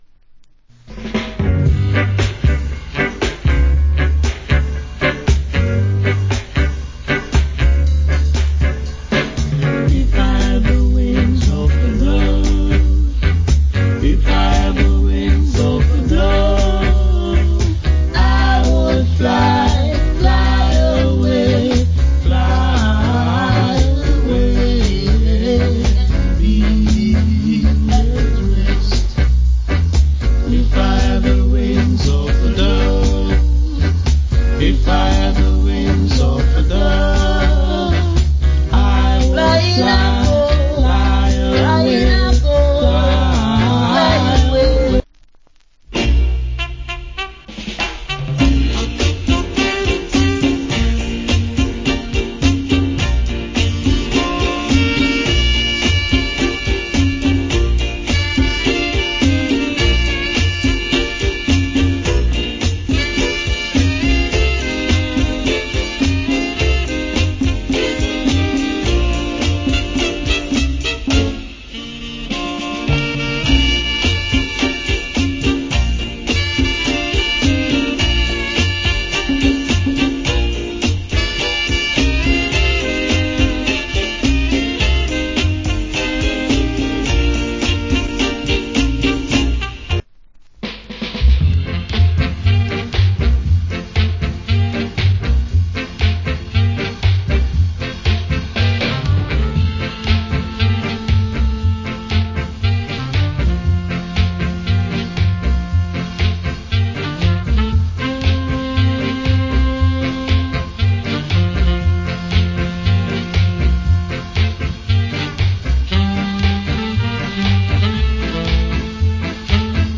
Nice Authentic Ska.